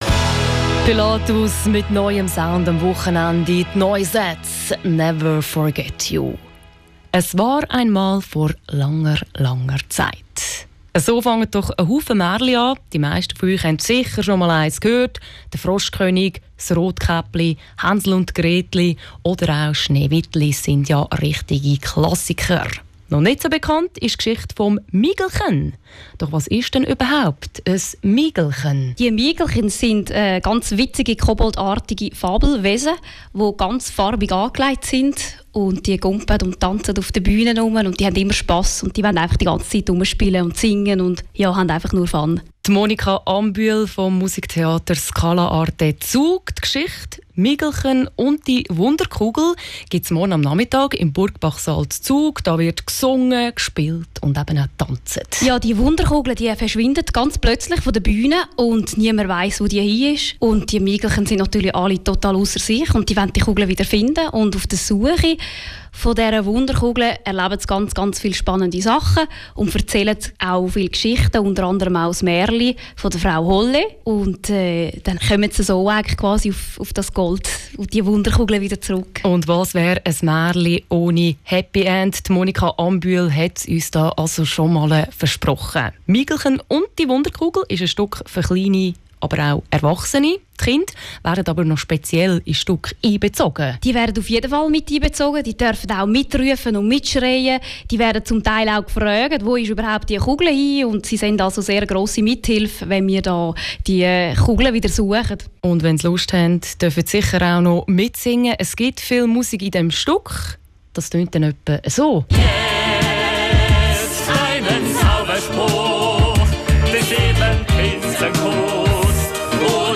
Interview_Migelchen.mp2